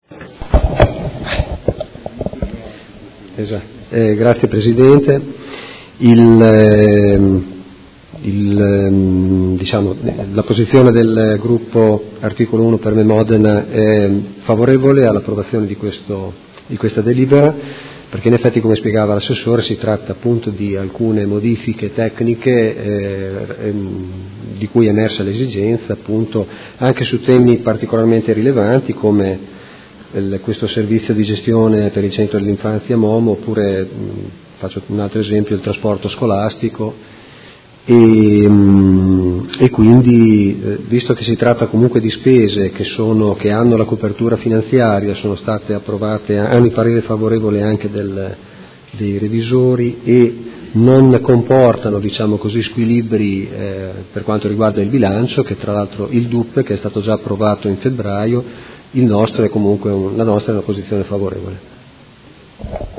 Seduta del 19/04/2018. Dichiarazione di voto su proposta di deliberazione: Aggiornamento del DUP 2018-2020, Programma biennale degli acquisti di forniture e servizi 2018-19